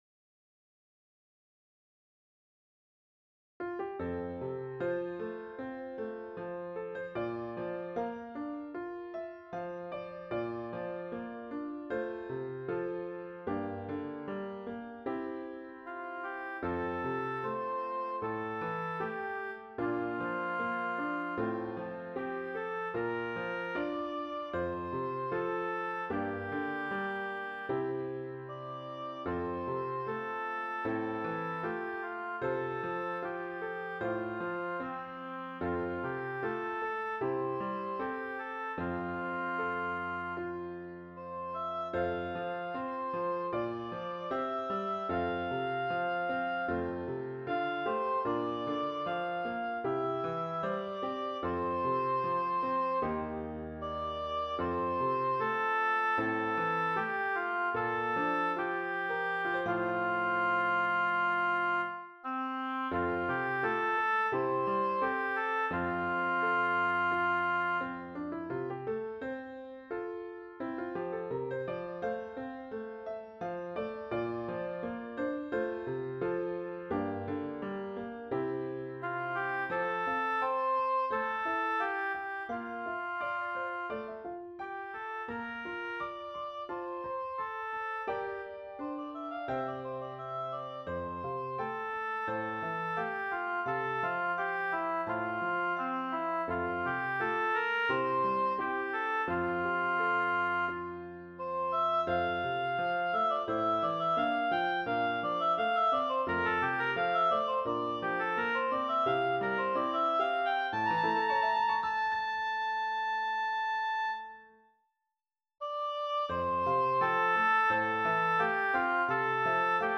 Intermediate Instrumental Solo with Piano Accompaniment.
Christian, Gospel, Sacred, Folk.
put to a flowing folk setting.